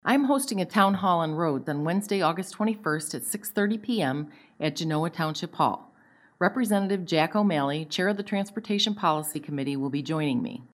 Rep. Bollin talks about the townhall meeting she will hold this coming Wednesday, August 21, at the Genoa Township Hall on Door Road, on roads and transportation.